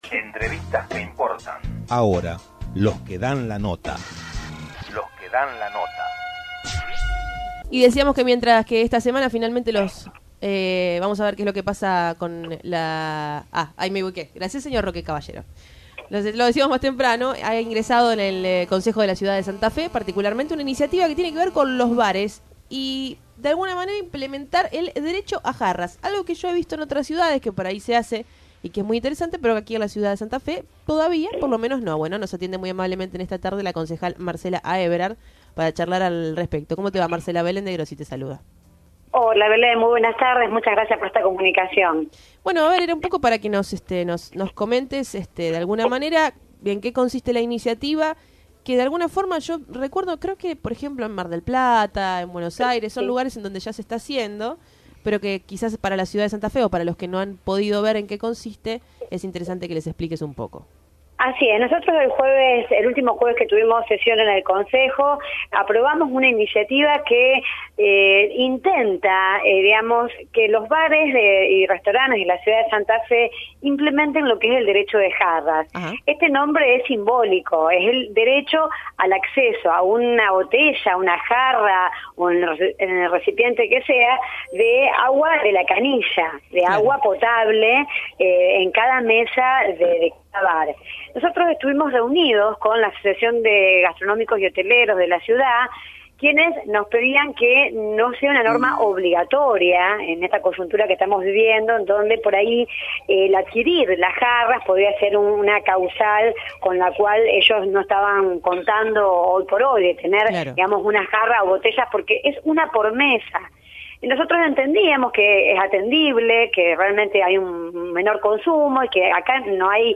La Concejal Marcela Aeberhard nos comenta sobre la iniciativa del derecho de jarra en la ciudad de Santa Fe.